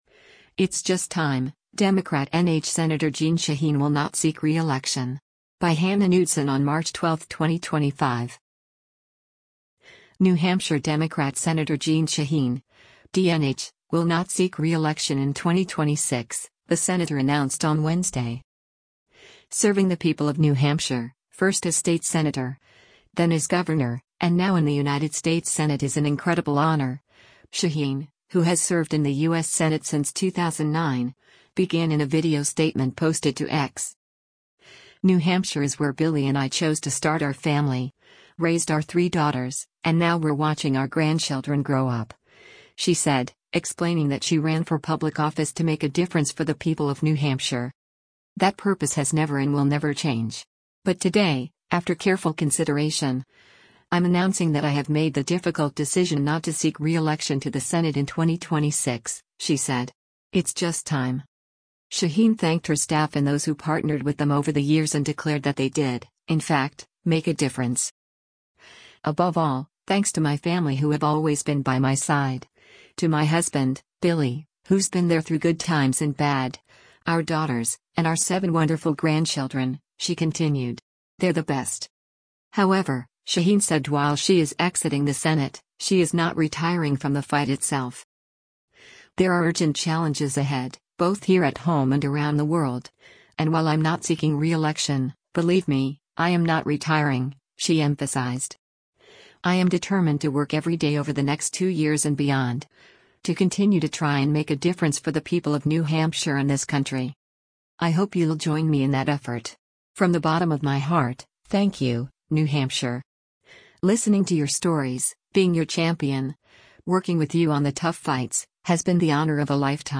“Serving the people of New Hampshire, first as state senator, then as governor, and now in the United States Senate is an incredible honor,” Shaheen, who has served in the U.S. Senate since 2009, began in a video statement posted to X.